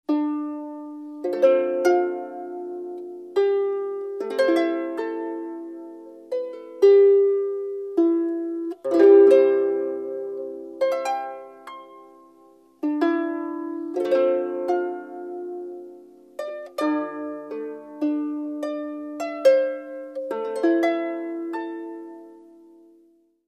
Voice, Irish small harp, Welsh small harp, tenor recorder, treble recorder
All others recorded at Bent Sound Studio, Victoria, BC Canada